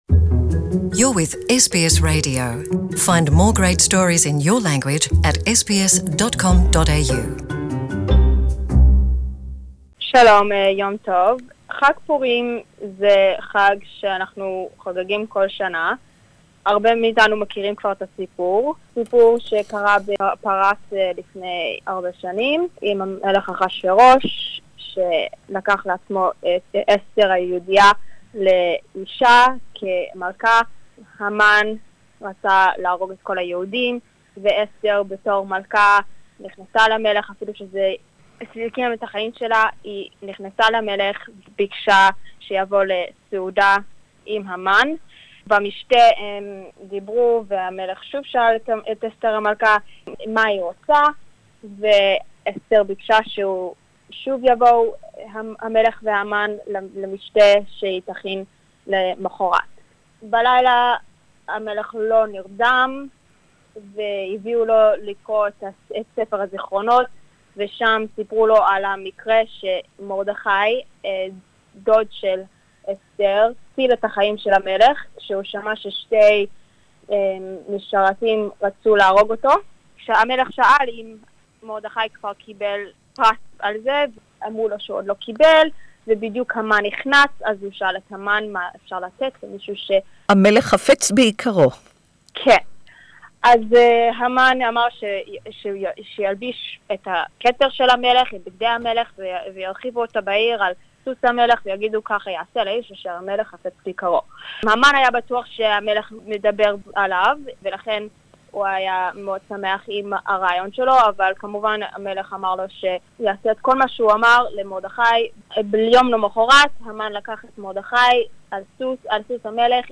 We will celebrate Purim with you today with Purim songs, history and customs